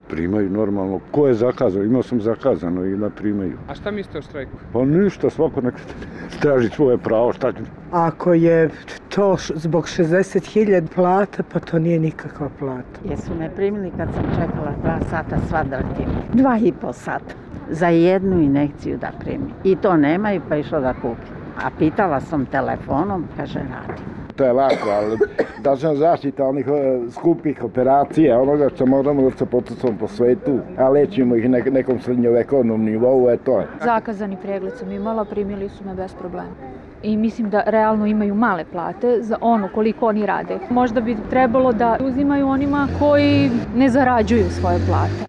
Neki od Beograđana, koji su u domu zdravlja na Dorćolu potražili lekarsku pomoć, rekli su za RSE da znaju da većina lekara ne radi ali da ih je nužda naterala da dođu:
Građani o štrajku lekara